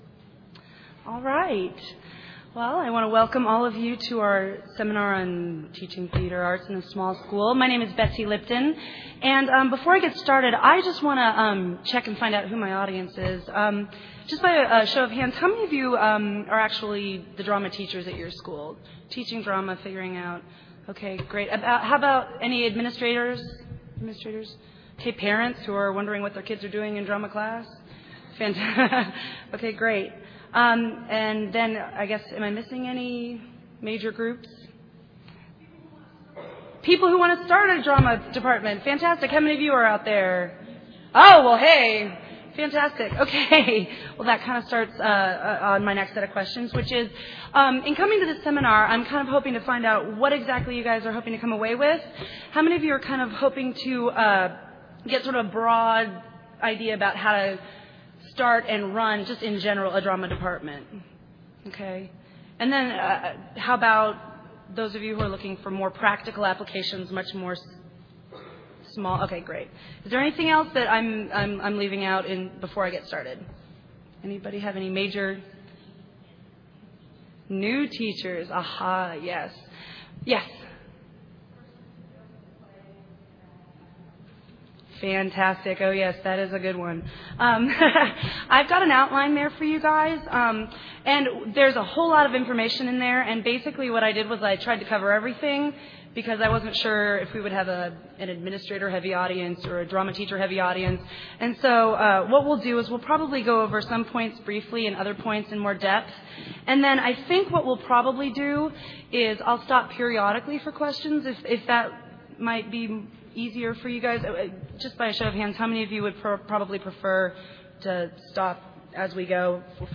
2008 Workshop Talk | 1:06:52 | 7-12, Art & Music